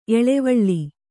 ♪ eḷevaḷḷi